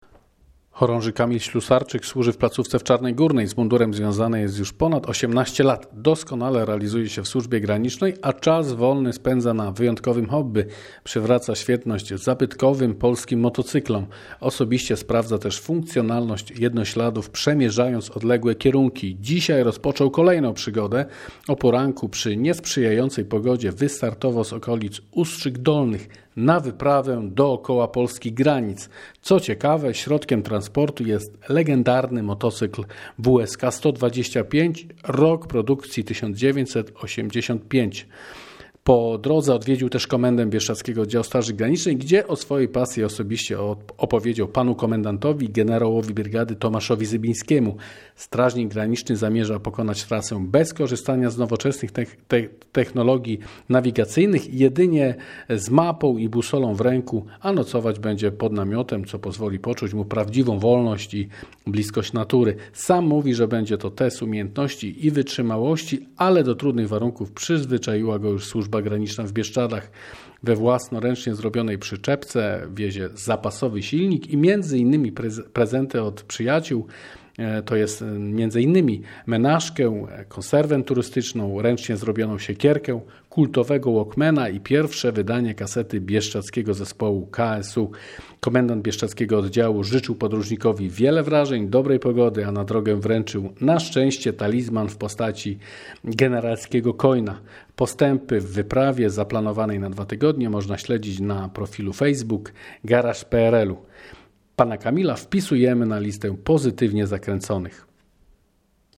ROZMAWIALIŚMY W MOTOMAGAZYNIE